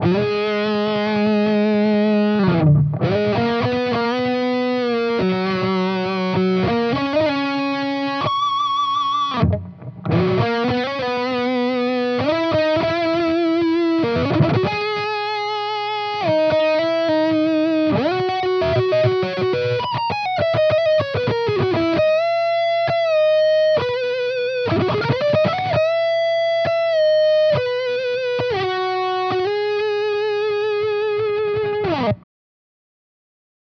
Here are a few quick demos of my custom overdrive/distortion pedals for your listening pleasure.
Blue pedal with boost
The blue pedal worked fantastically with a boost in front, but the purple one just got out of hand with so much gain.
I used a Sennheiser MD441-U microphone on-axis, edge of speaker cap, and about an inch away from the grill.
blue-pedal-with-boost.wav